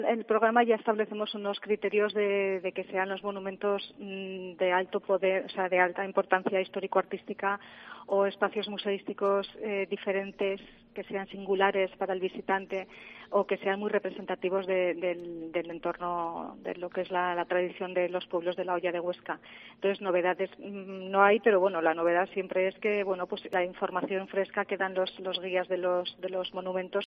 Aí lo ha explicado en COPE Huesca